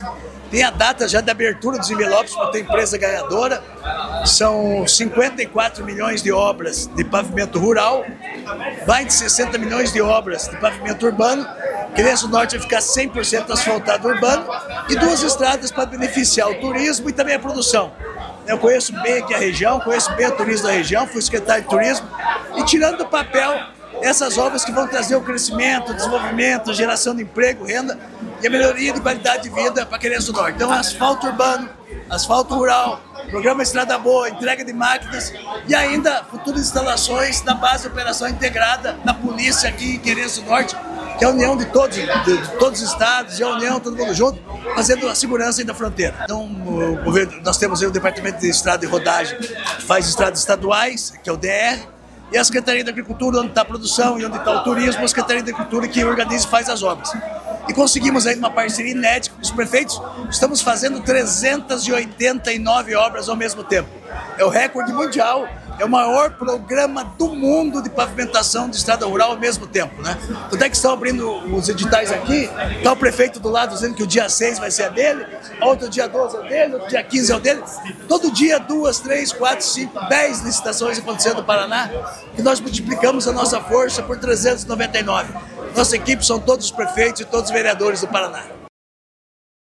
Sonora do secretário da Agricultura e do Abastecimento, Márcio Nunes, sobre o anúncio de quase R$ 120 milhões em investimentos para Querência do Norte